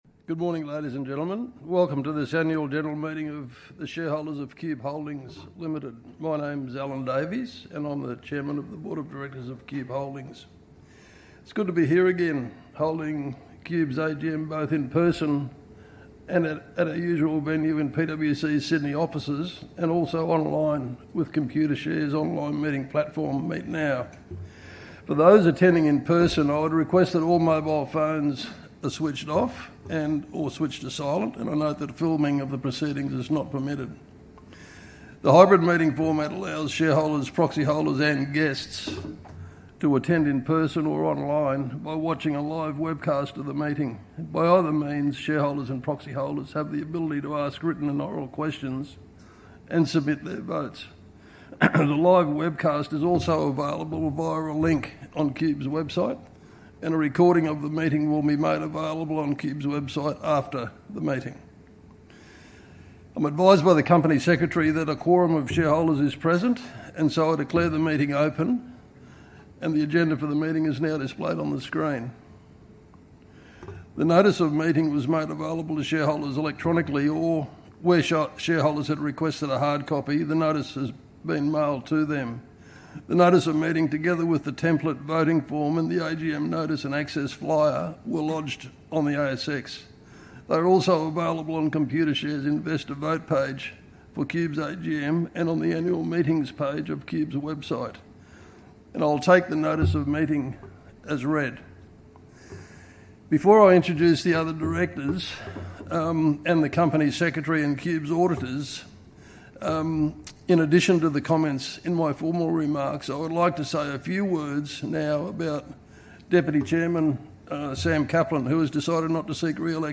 Annual-General-Meeting-2024-Voice-Recording.mp3